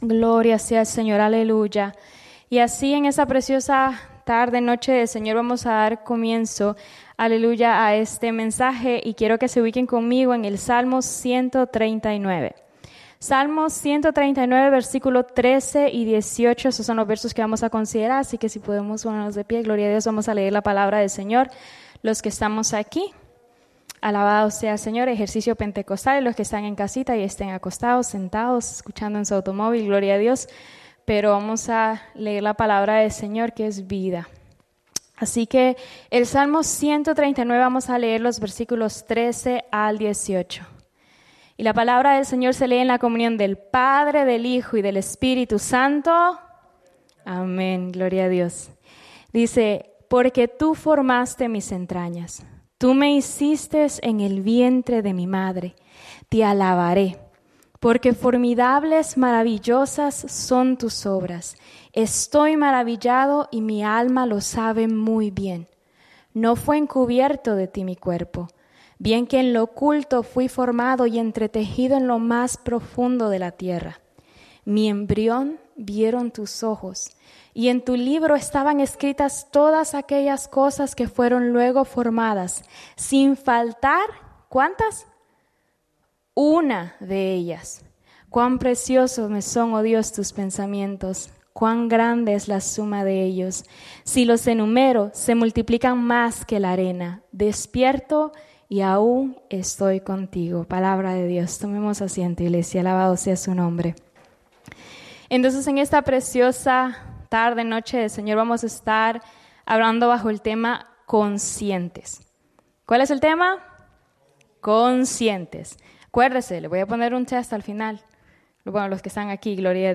grabado el 09/25/2020 en la Iglesia Misión Evangélica en Souderton, PA